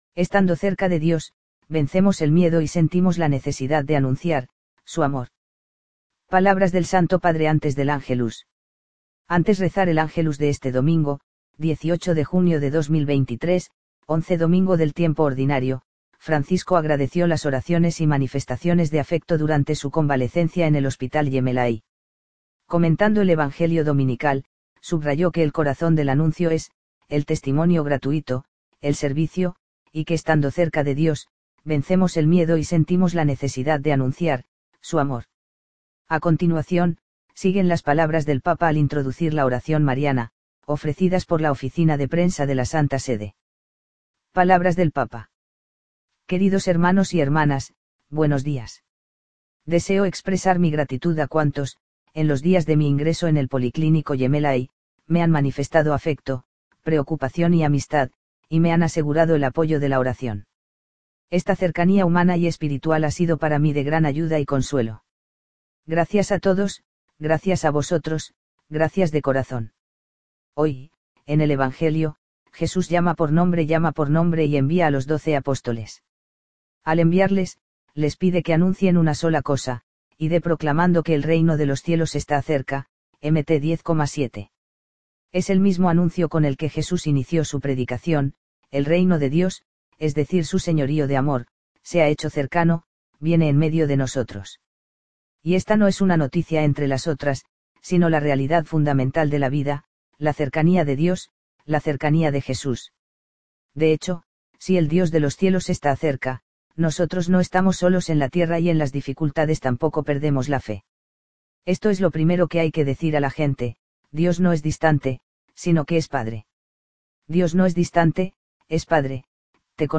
Palabras del santo Padre antes del Ángelus